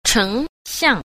7. 成象 – chéng xiàng – thành tượng (ấn tượng, hình ảnh)